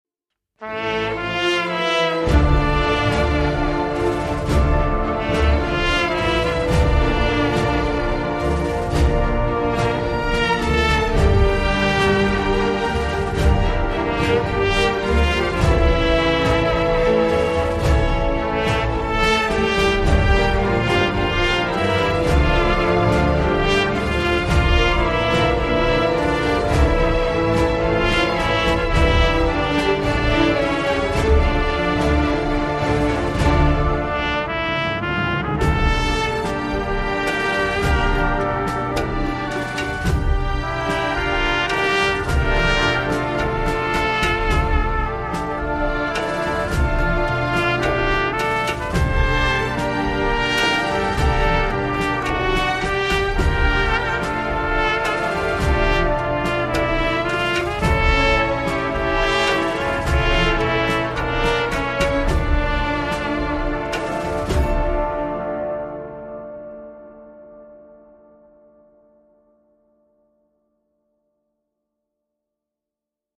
با نغمه‌هایی سرشار از احساس و عشق